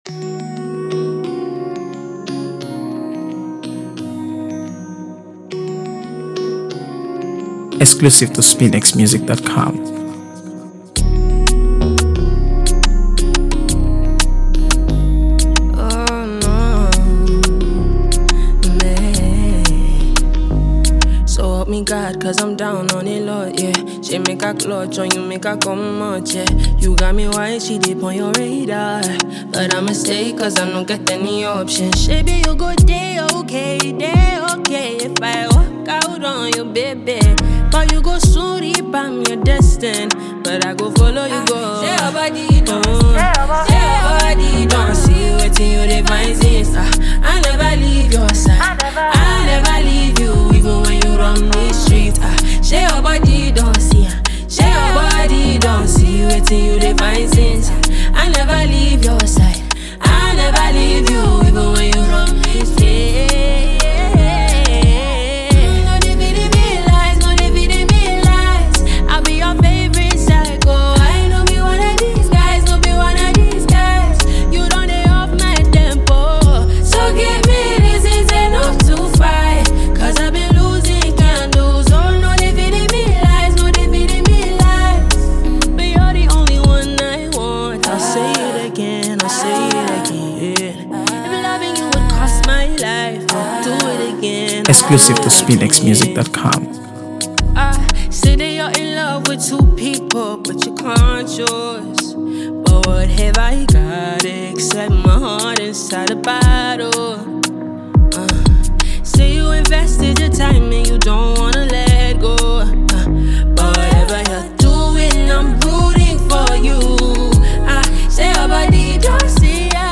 AfroBeats | AfroBeats songs
Fast-rising Nigerian singer and songwriter
If you love soulful, well-crafted music